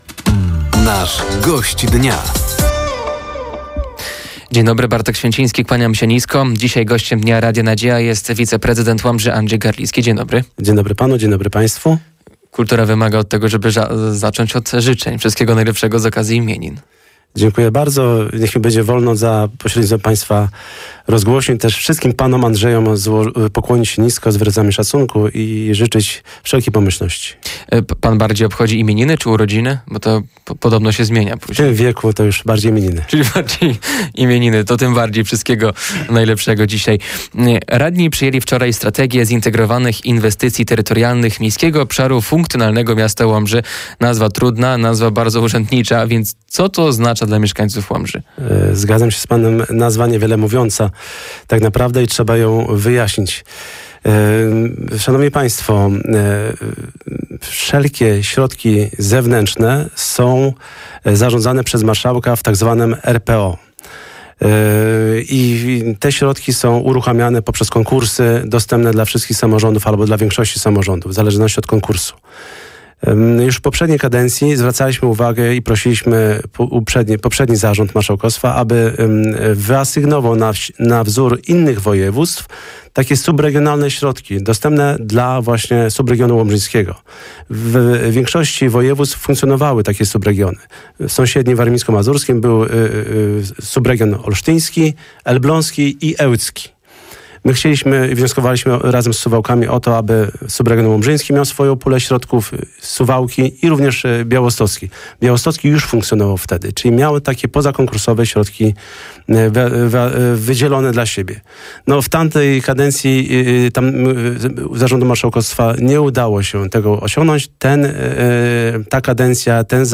Gościem Dnia Radia Nadzieja był wiceprezydent Łomży, Andrzej Garlicki. Tematem rozmowy było między innymi przedłużenie bulwarów, przebudowa budynku przy ul. Wesołej i druga edycja konkursu Zabiznesuj w Łomży.